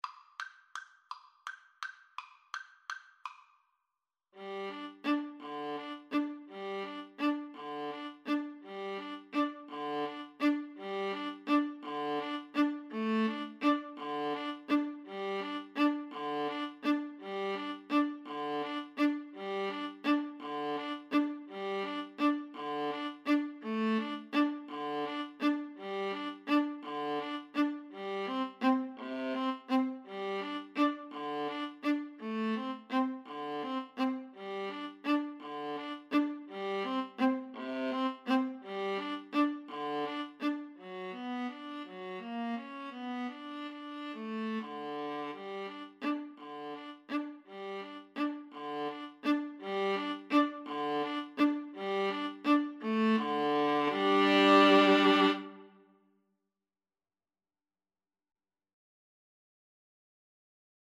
Tempo di valse =168
3/4 (View more 3/4 Music)
Viola Duet  (View more Easy Viola Duet Music)
Classical (View more Classical Viola Duet Music)